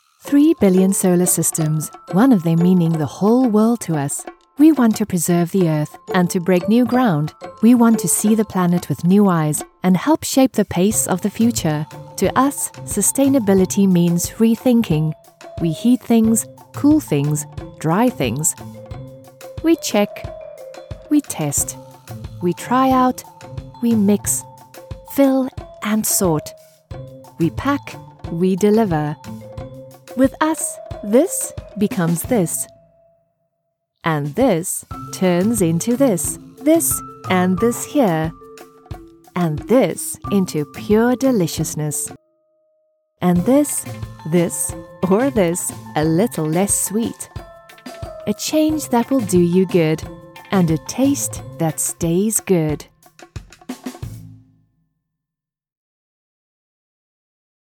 Unternehmensvideos
Meine Stimme kann zugänglich und freundlich, bestimmend, warm und glaubwürdig oder auch schrullig und lebhaft sein.
Schallisolierter Raum
HochMezzosopran